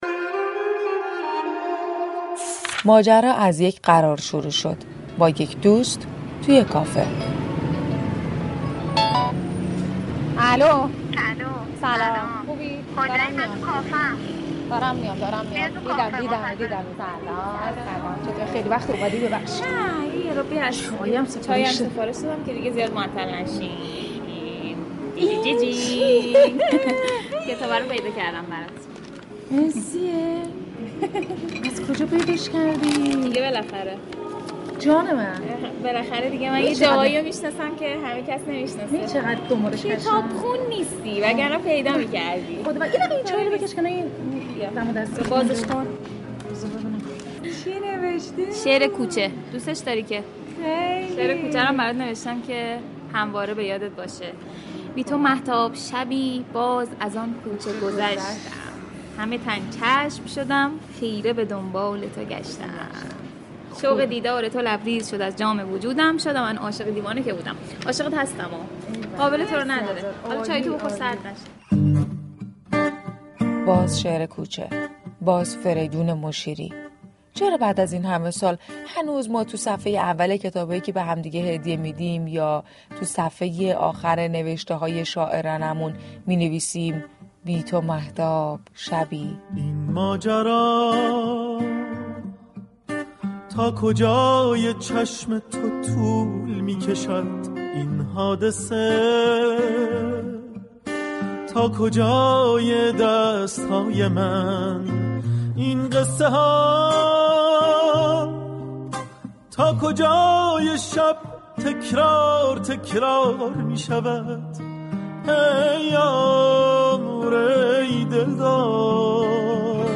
به گزارش روابط عمومی رادیو صبا ،"ماجرا " عنوان یكی از برنامه های جدید رادیو صبا است كه در قالب مستند گزارشی به بررسی دلایل شهرت افراد و ماجرا های معروف می پردازد .